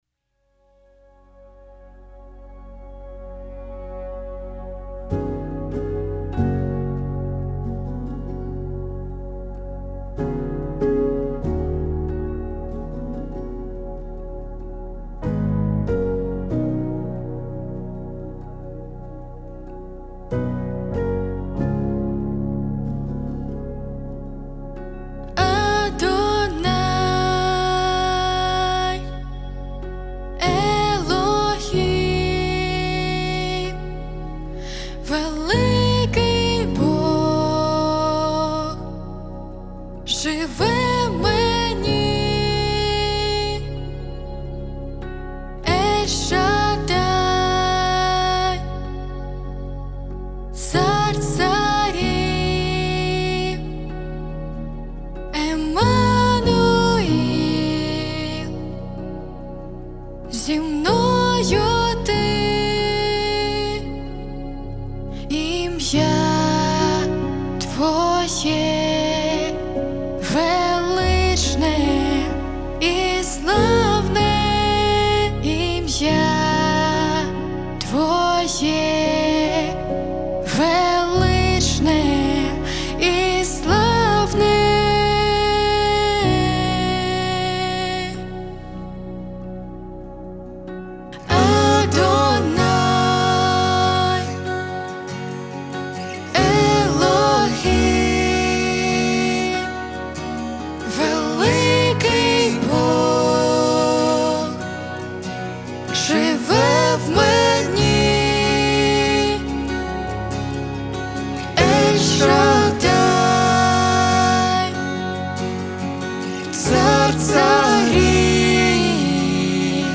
114 просмотров 154 прослушивания 12 скачиваний BPM: 71